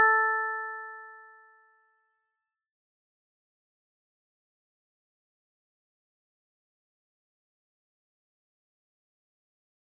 つまり 440 Hz を強さ 0.4, 880 Hz を強さ 0.7, 1320 Hz を 強さ 1.0, 1760 Hz を強さ 1.2 とした音. さらに全体に exp(-t) をかけて減衰させています. ラの音に聞こえますが単独の sin 音とは音色が違います.